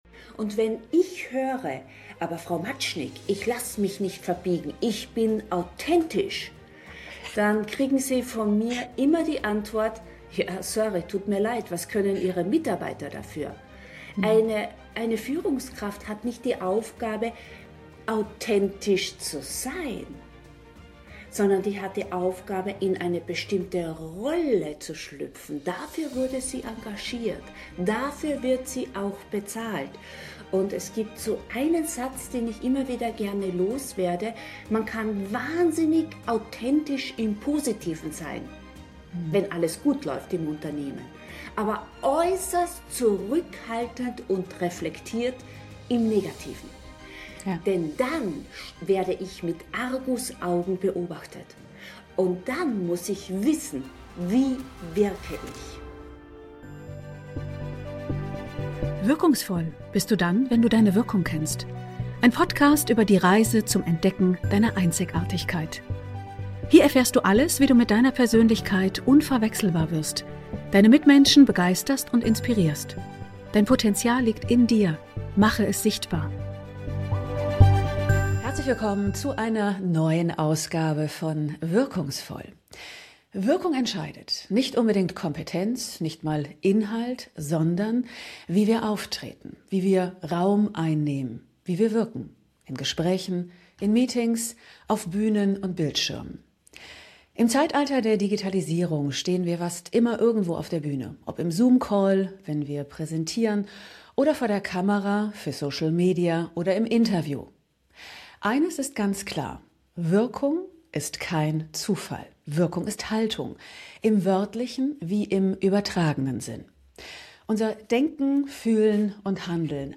Ein Gespräch über mentale Stärke, Selbstwahrnehmung und die Macht der nonverbalen Kommunikation.